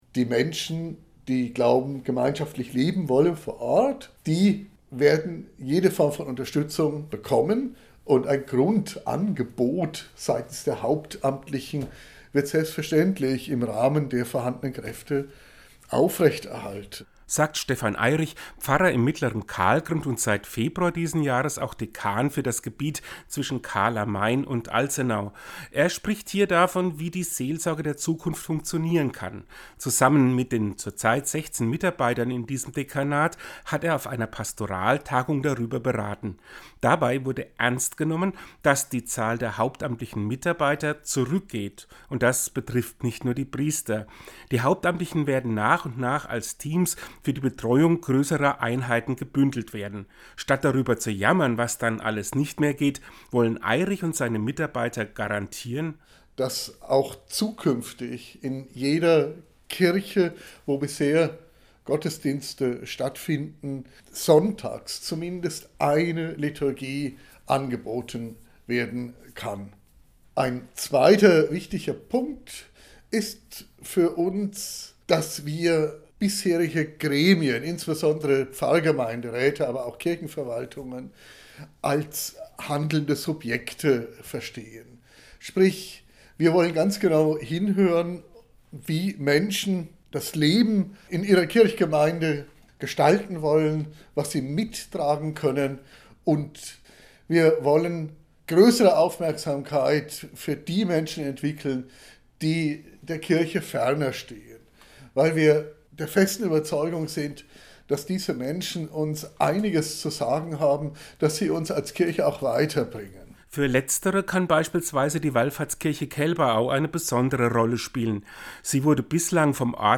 Seinen Radiobeitrag finden Sie unten als Download.